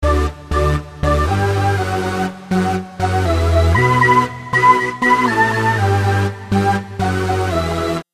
A New Pack Of A Random Sound I Made Somehow » Ghostly Ghouly Screaming
描述：Paulstrecthed recording of a room of people laughing (Audacity). Sounds pretty creepy.
标签： ghosts hell demons creepy laughs eerie screams
声道立体声